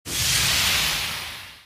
soceress_skill_icyfraction_01_intro.mp3